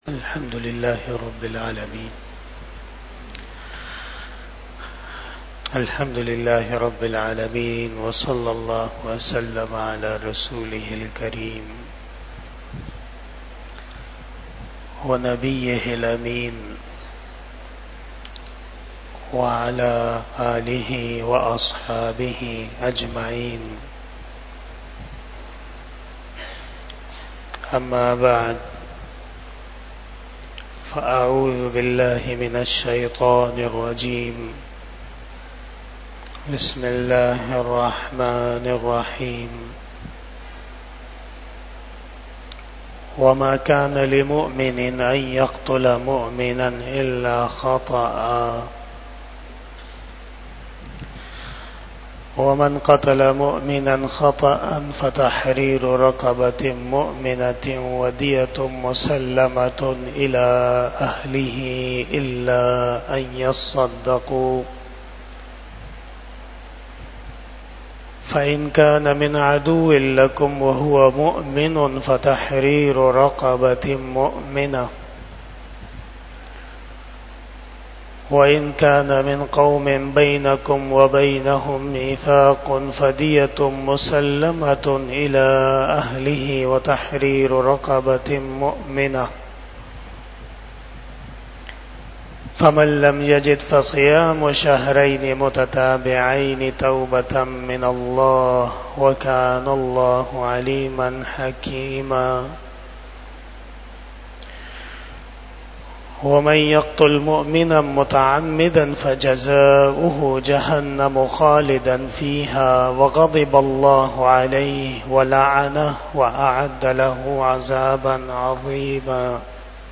29 Shab E Jummah Bayan 29 August 2024 (24 Safar 1446 HJ)